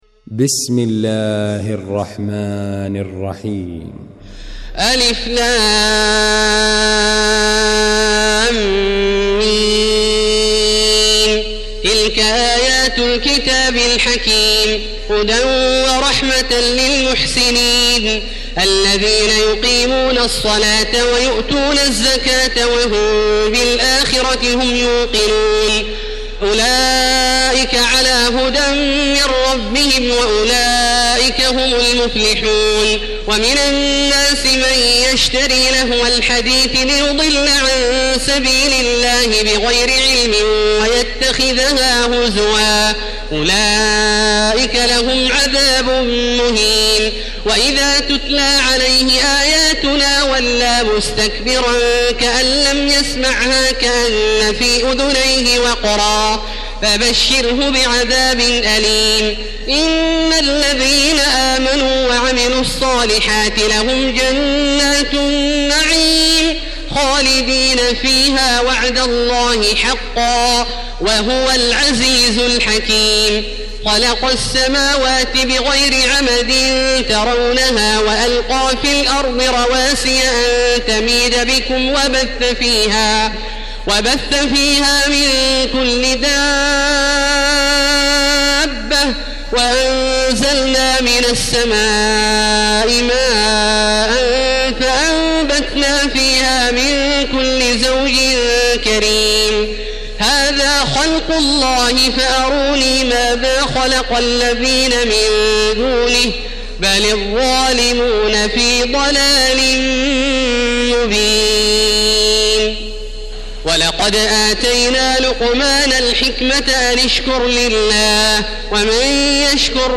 المكان: المسجد الحرام الشيخ: فضيلة الشيخ عبدالله الجهني فضيلة الشيخ عبدالله الجهني لقمان